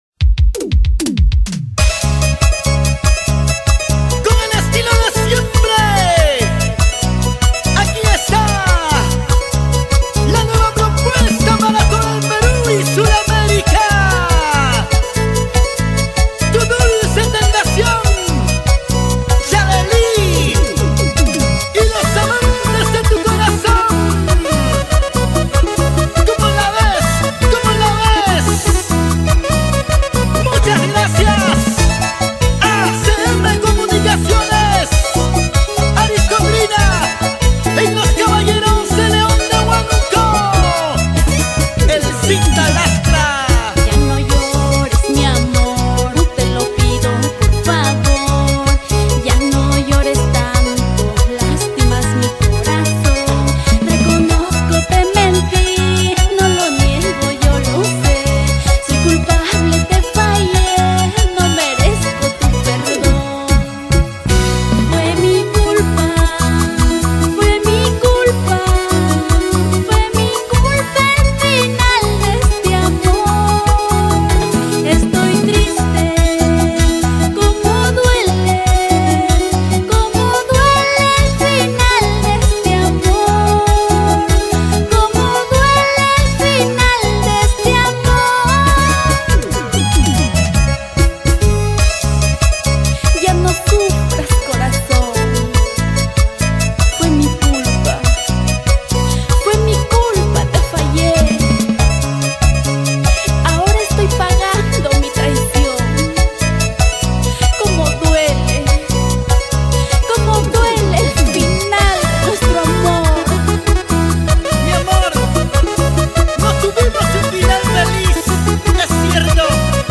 UNA VOZ FRESCA DEL HUAYNO SUREÑO 2017.